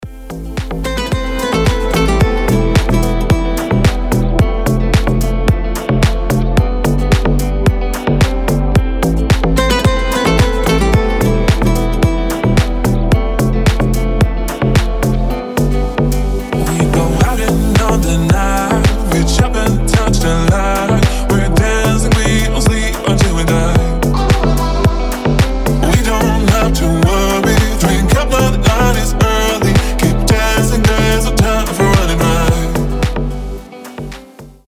• Качество: 320, Stereo
гитара
мужской голос
deep house
красивая мелодия
Chill